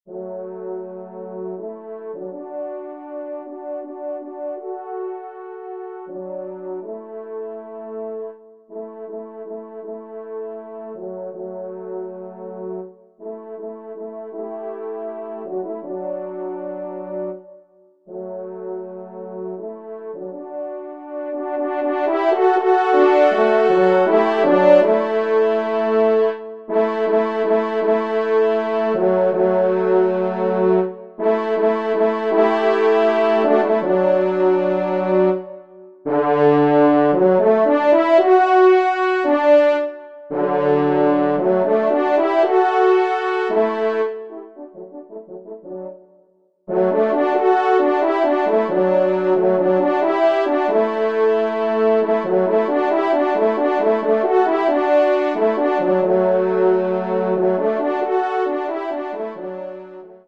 Genre :  Divertissement pour quatre Trompes ou Cors en Ré
Pupitre 2°Trompe